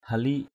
/ha-li:ʔ/ (đg. d.) trạt = rouler, rouleau. to roll; roller. halik hamu mâng halik hl{K hm~% m/ hl{K trạt ruộng bằng trạt = rouler la rizière avec un rouleau. roll...